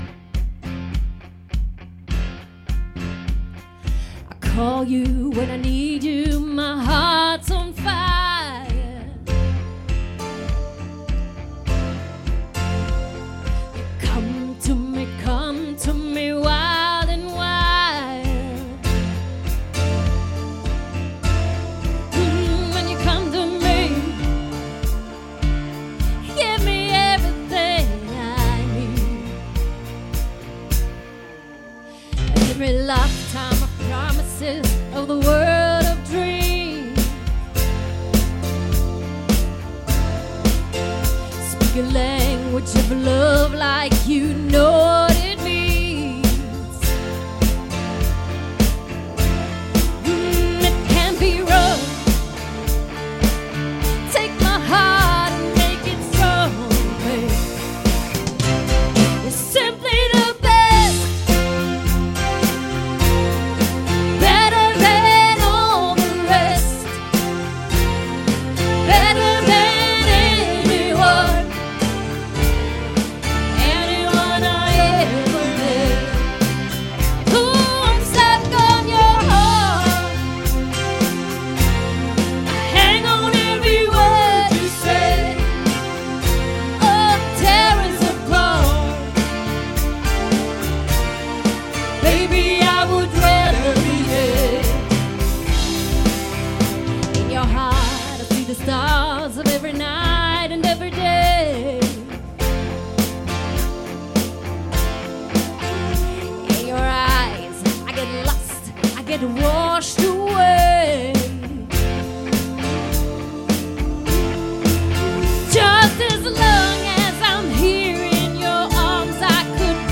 🎹 Keyboard & Gesang
🎸 E-Gitarre
🎸 Bass
🥁 Schlagzeug
• Rockband
• Coverband